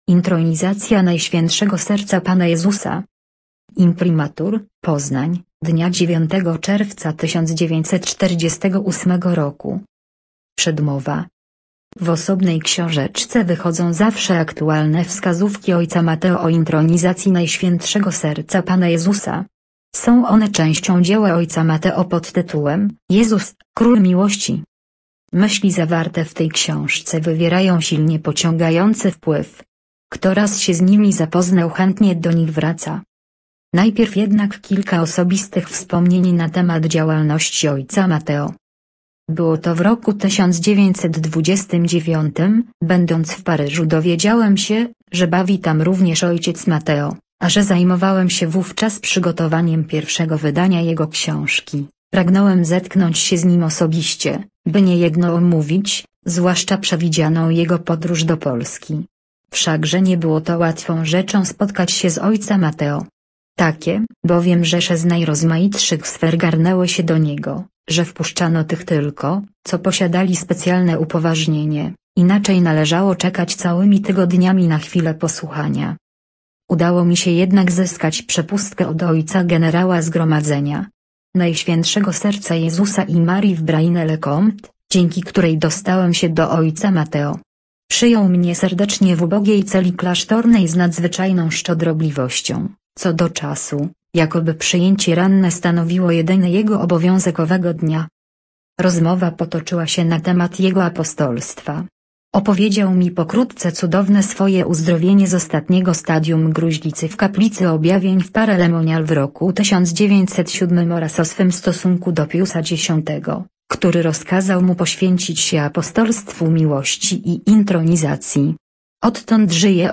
LEKTOR - PRZEDMOWA s. 3-4